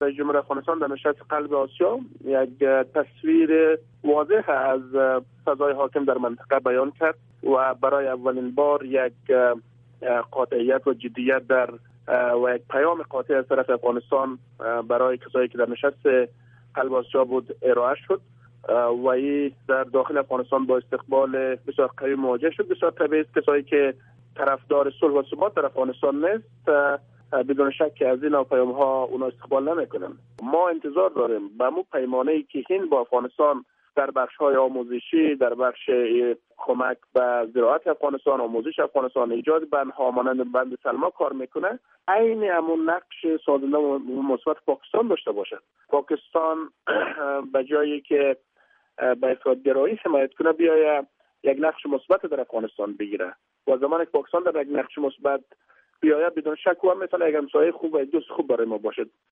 اظهارات شاه حسین مرتضوی، معاون سخنگوی رئیس جمهور افغانستان را در اینجا بشنوید